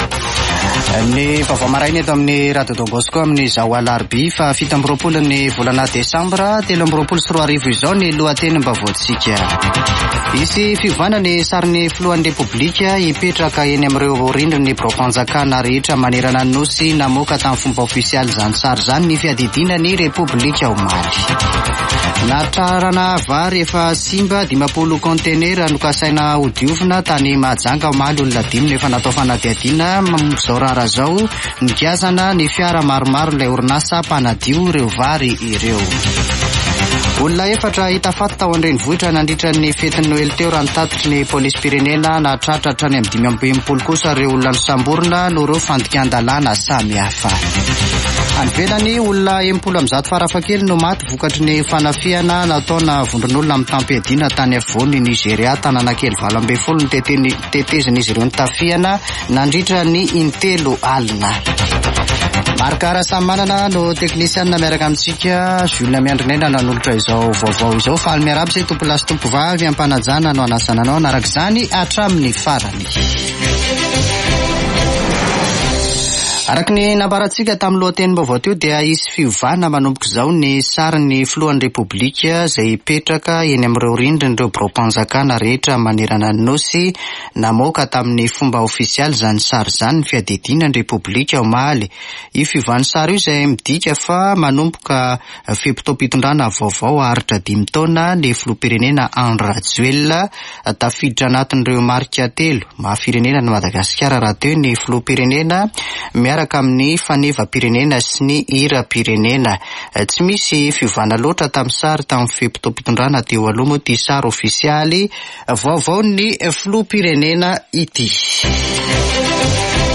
[Vaovao maraina] Alarobia 27 desambra 2023